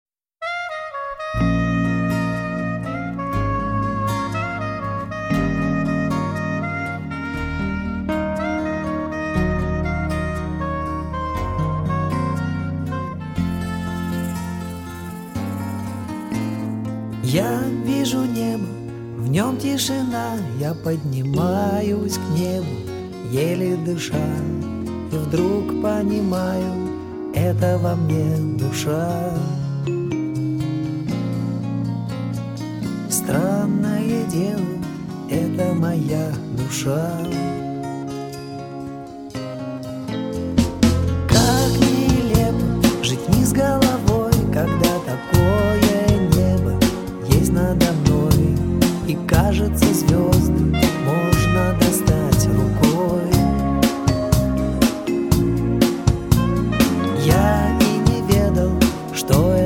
Рок
Новый цифровой ремастеринг.